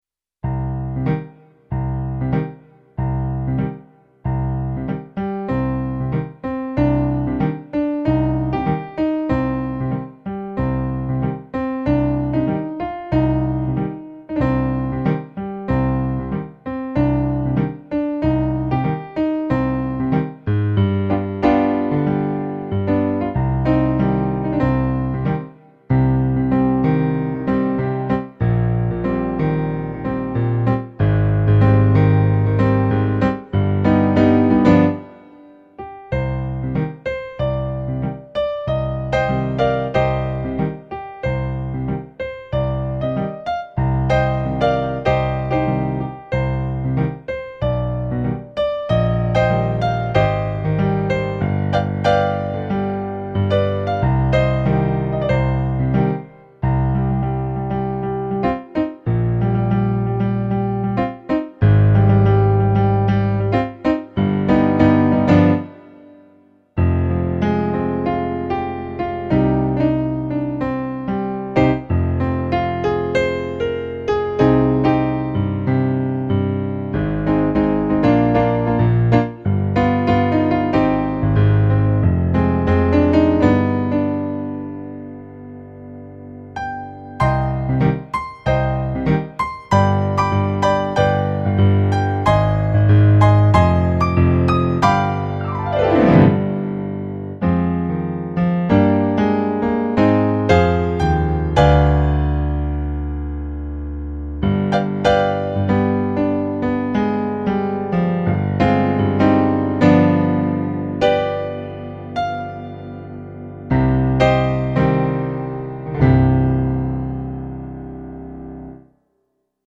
eight piano solo arrangements.  31 pages.
funk remix